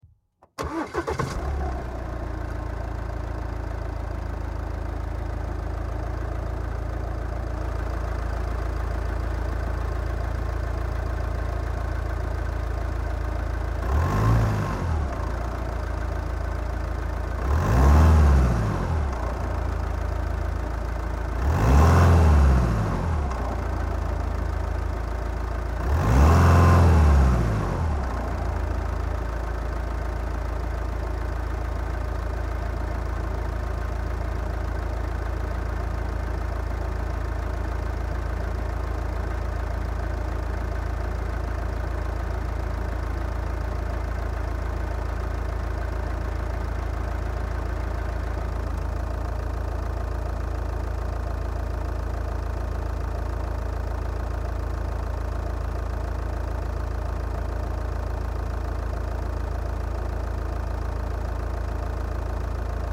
Звуки Audi A4
Характерный звук работы дизельного двигателя Ауди А4